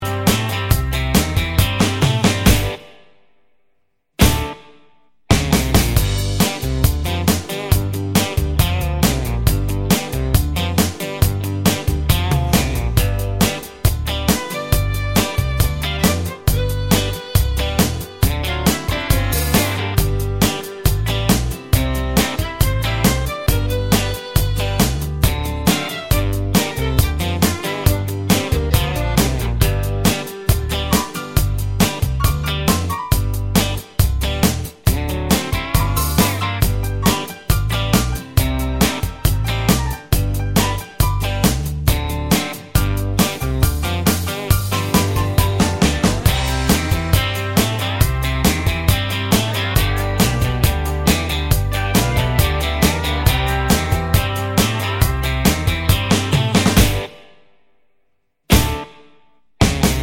no Backing Vocals Country (Male) 3:00 Buy £1.50